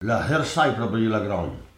Maraîchin
Locution